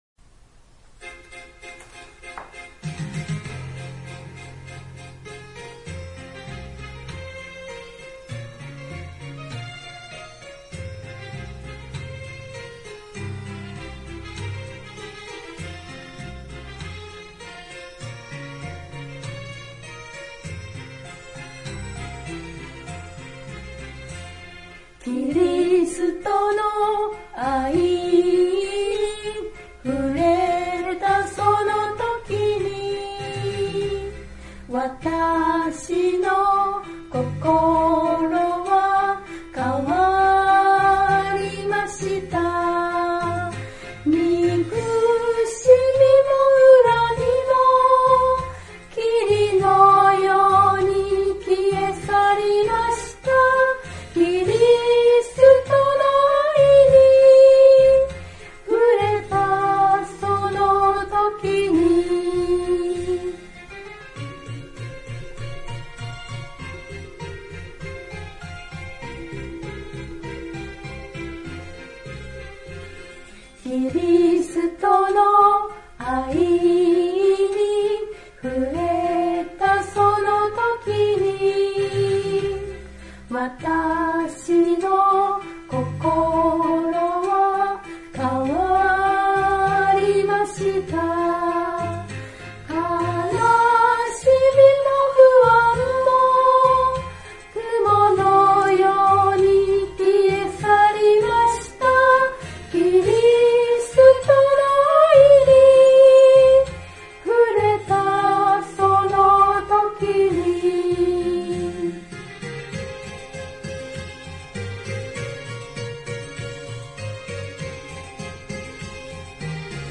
God bless you）より 唄
（徳島聖書キリスト集会集会員）